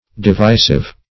Divisive \Di*vi"sive\, a. [Cf. F. divisif.]